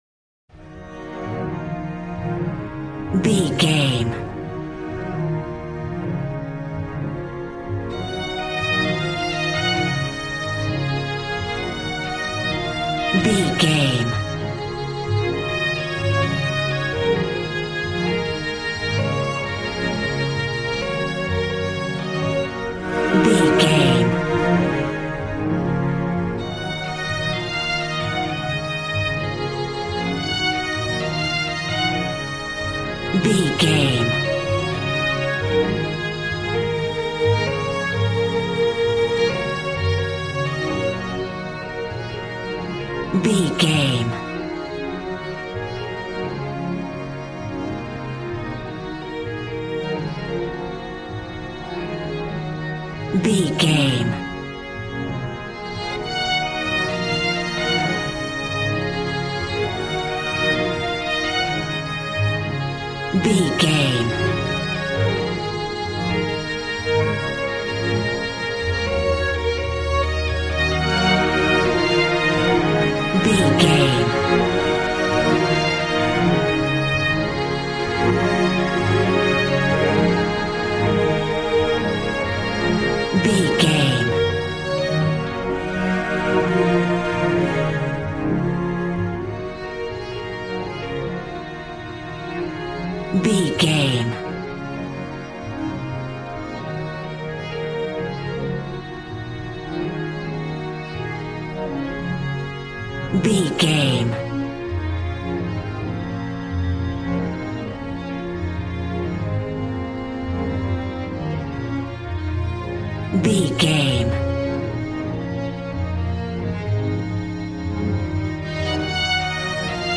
Aeolian/Minor
B♭
joyful
conga
80s